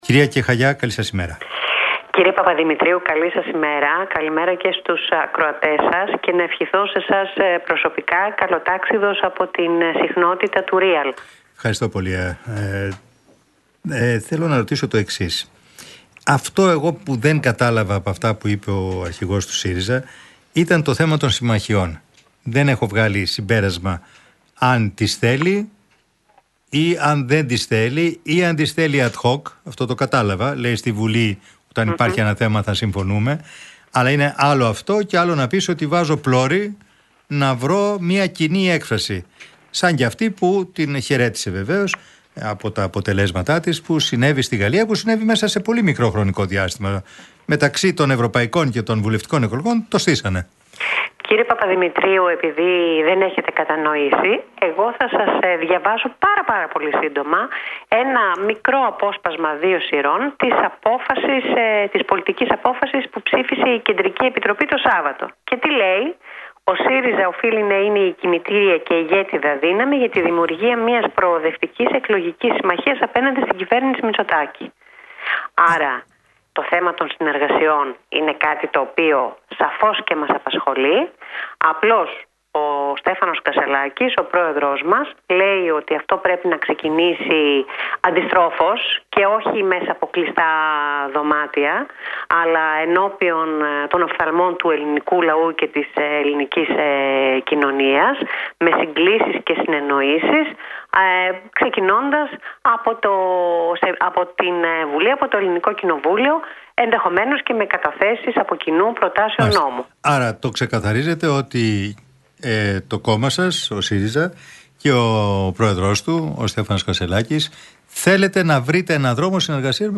Η εκπρόσωπος Τύπου του ΣΥΡΙΖΑ-ΠΣ, Βούλα Κεχαγιά, στη συνέντευξή της στον Real FM και στον δημοσιογράφο Μπάμπη Παπαδημητρίου, την Τρίτη 9 Ιουλίου 2024, είπε αρχικά «Εγώ θα σας διαβάσω πάρα – πάρα πολύ σύντομα ένα μικρό απόσπασμα δύο σειρών της απόφασης της πολιτικής απόφασης που ψήφισε η Κεντρική Επιτροπή το Σάββατο.